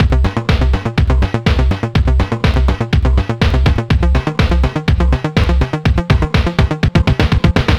Old Order C 123.wav